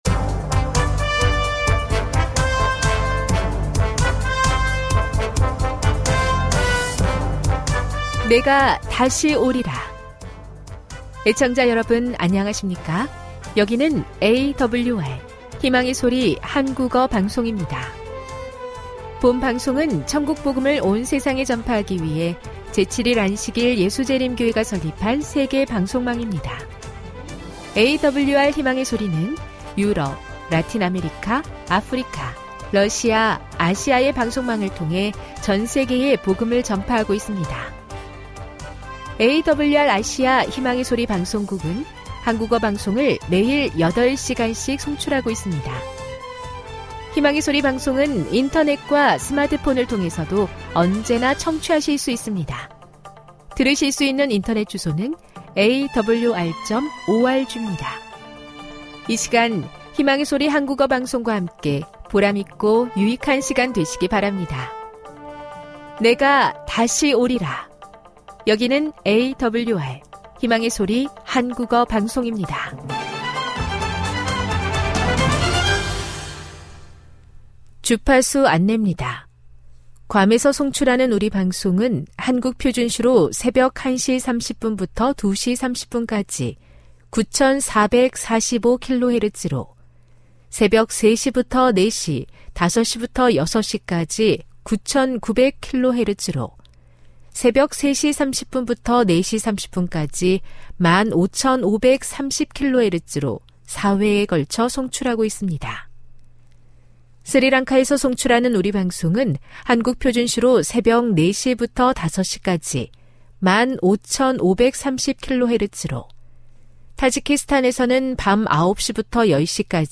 1 설교, 말씀묵상 58:08